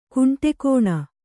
♪ kuṇṭekōṇa